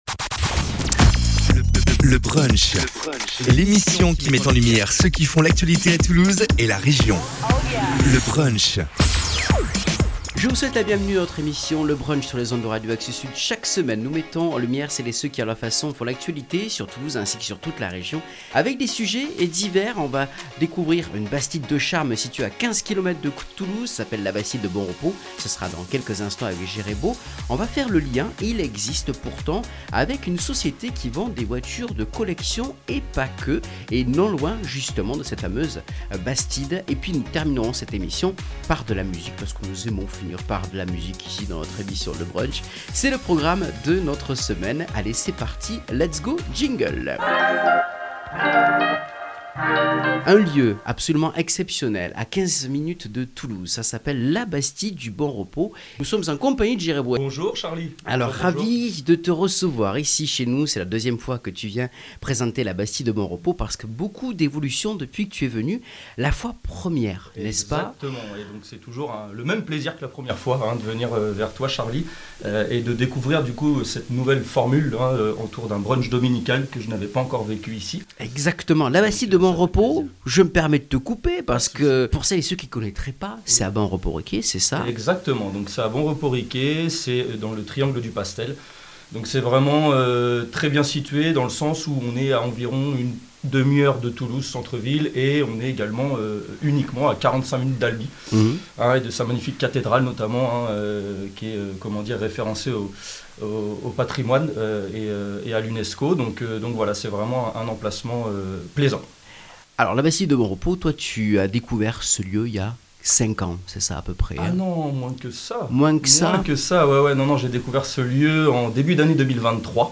Découverte d’un superbe lieu, Labastide de Bonrepos qui propose location du site pour des vacances et des soirées événementielles. La Chanteuse et créatrice de spectacle Clara Morgane est à nos côtés pour parler de son spectacle “Au 7eme” qui se déroulera le 22 Mai à Labastide de Bonrepos. Un garage automobile pas comme les autres, Pro Cars Sélections qui met à la vente des véhicules de collection de tous types.